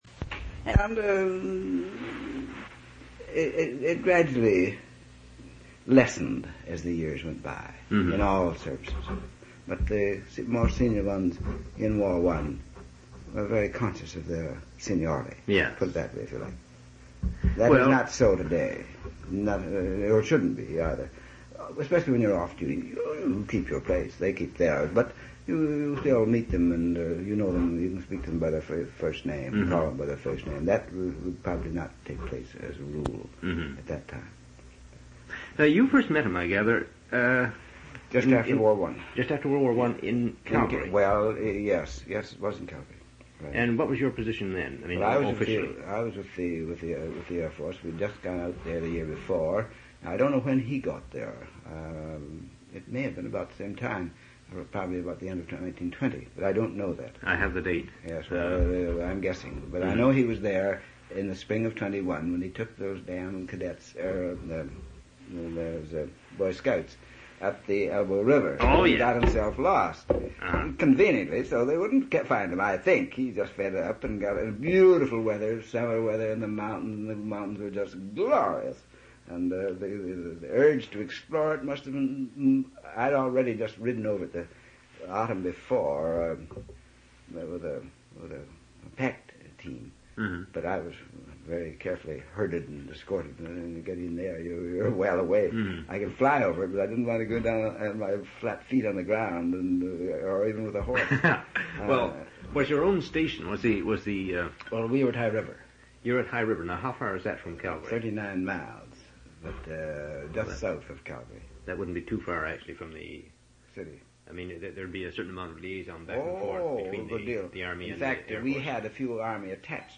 One original sound tape reel (ca. 46 min.) : 1 7/8 ips, 2 track, mono.
oral histories (literary genre) reminiscences sound recordings interviews